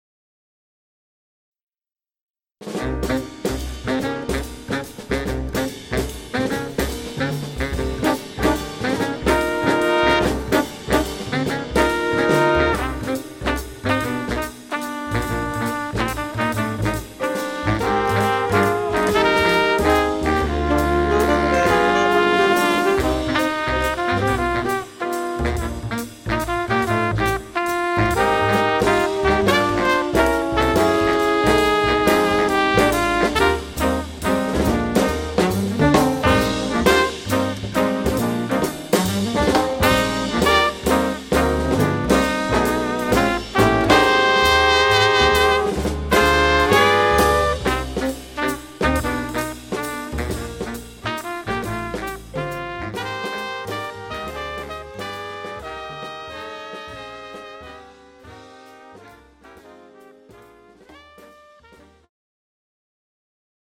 The Best In British Jazz
Recorded at Red Gables Studio, London 2010